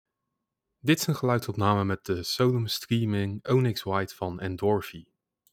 Microphone typeCondenser
Opname Endorfy Solum Streaming Onyx White
Echter hoor ik nu wel degelijk verschil en mijn stem komt veel duidelijker over, maar valt er minder diepte in te horen. Hiermee bedoel ik dat het geluid wat vlakker klinkt dan de Solum Studio wat in dit geval een voordeel is, dit maakt namelijk het opname geluid een stuk duidelijker hoorbaar.